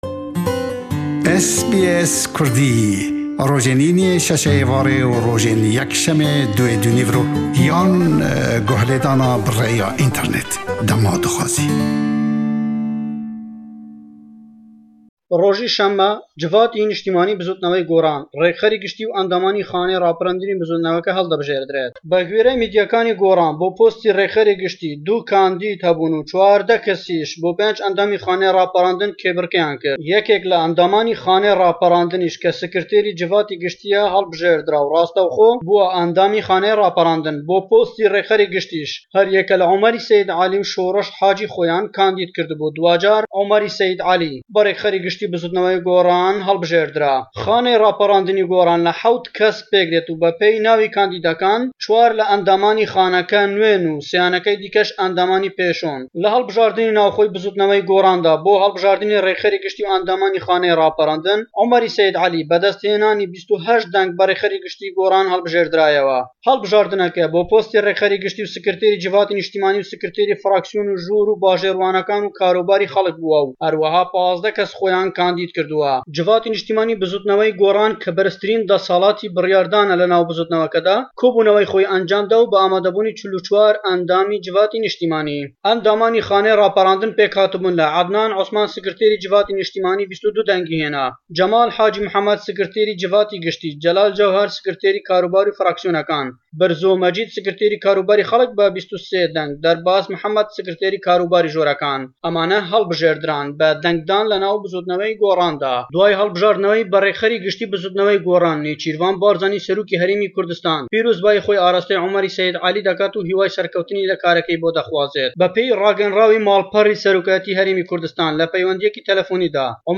Raportî
le Hewlêre we, bas le helbijartinekan dekat bo serokayetî Bizûtnewey Gorran.